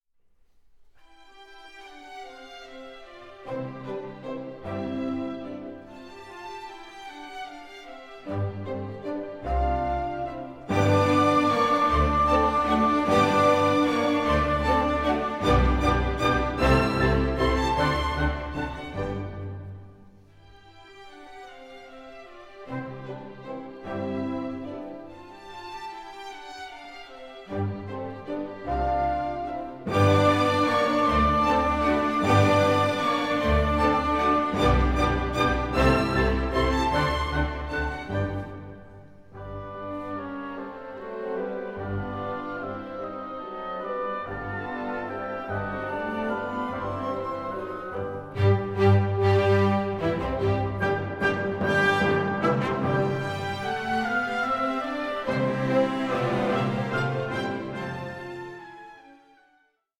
Molto allegro 11:33